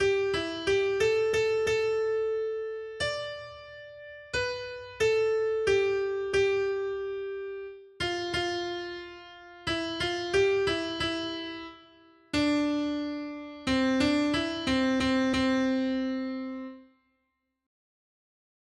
Noty Štítky, zpěvníky ol649.pdf responsoriální žalm Žaltář (Olejník) 649 Skrýt akordy R: Hospodin popatřil z nebe na zem. 1.